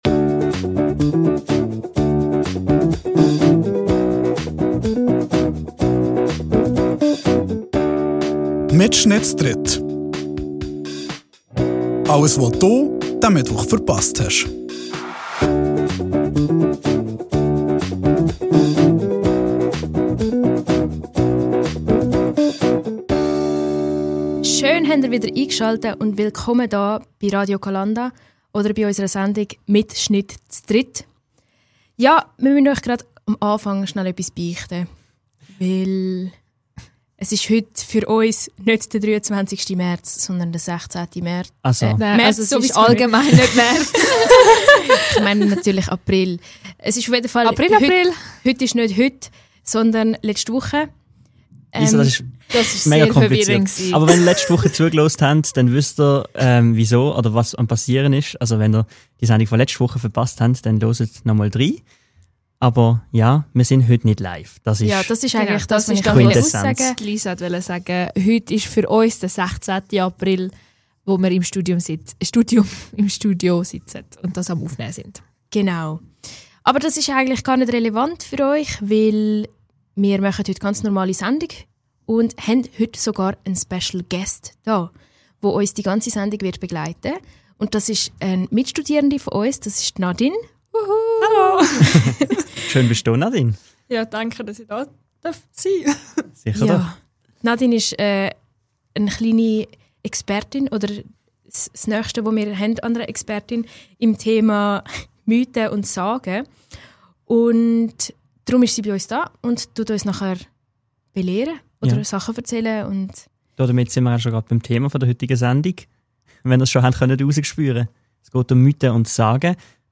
Die Wuche gahts bi MittSchnitt z'dritt um Sagene. Für das isch sogar e Gästin mit Expertise im Studio.